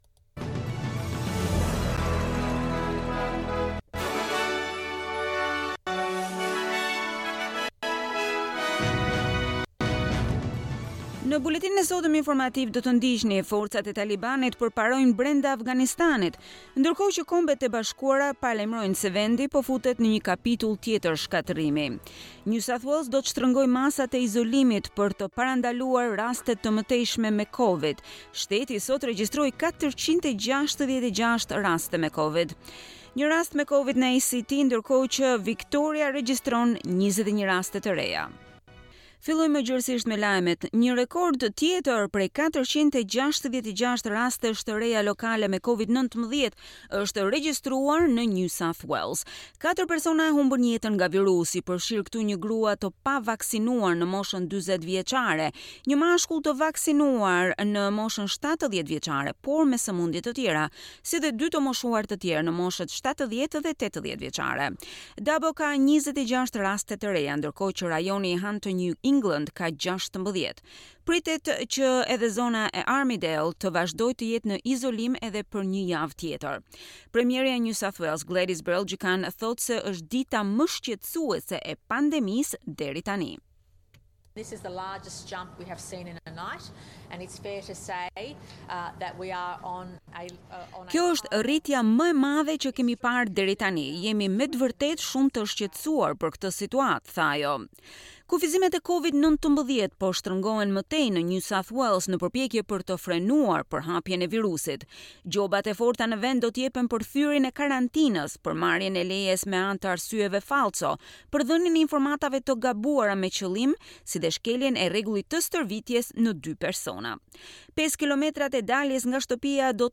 SBS News Bulletin in Albanian - 14 August 2021